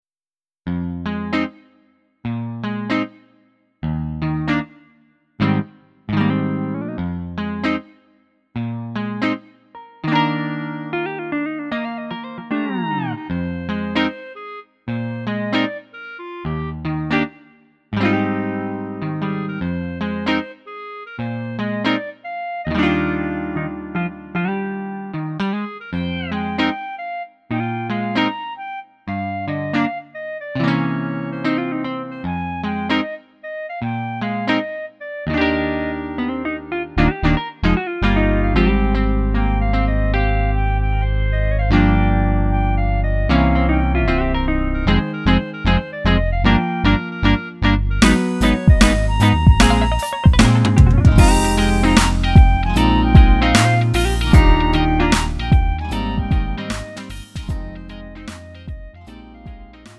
음정 -1키 3:53
장르 구분 Lite MR